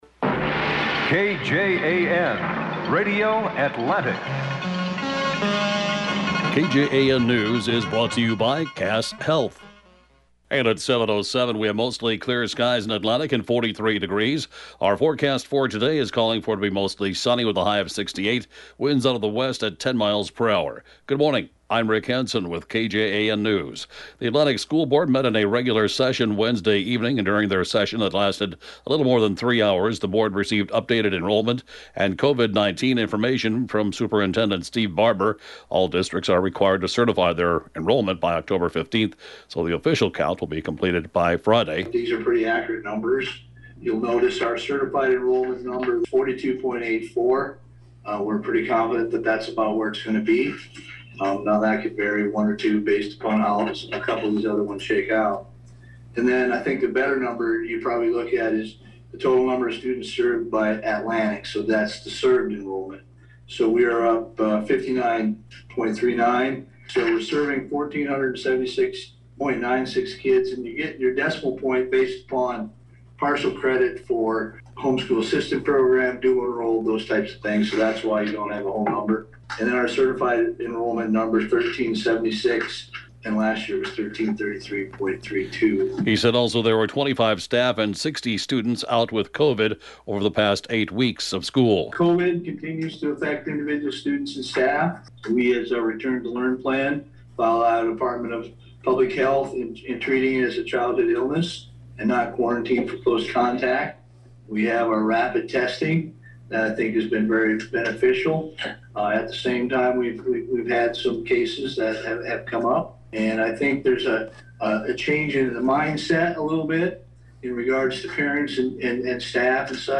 (Podcast) KJAN morning News, 10/14/21